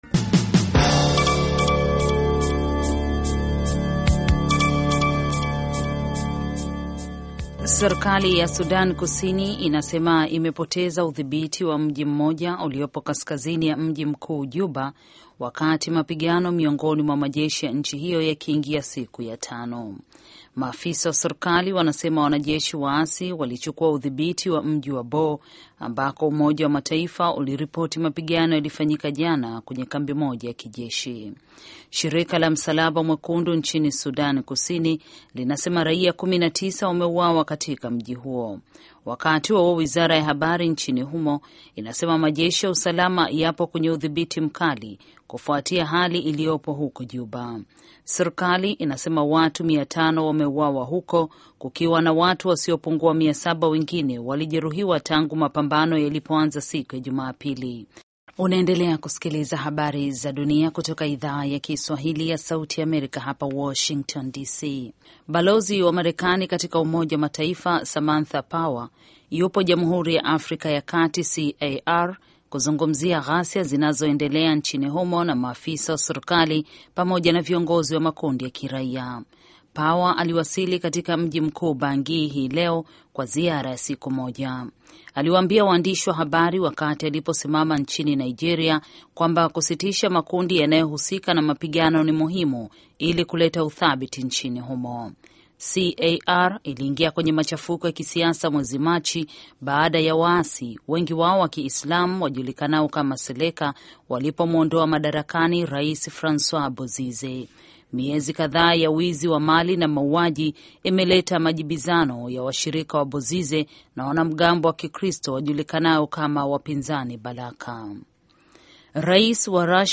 Taarifa ya Habari VOA Swahili - 6:43